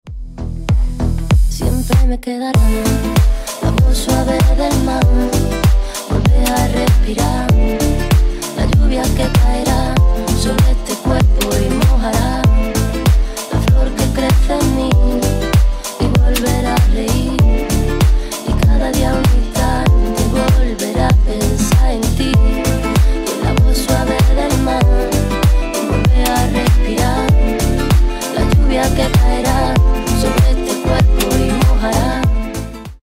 • Качество: 128, Stereo
красивые
женский вокал
deep house
чувственные